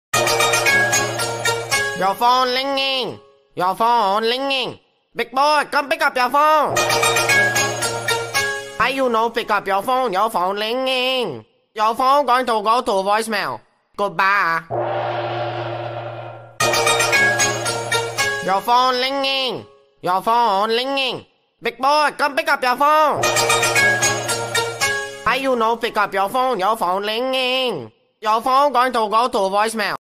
YOUR-PHONE-LINGING.wav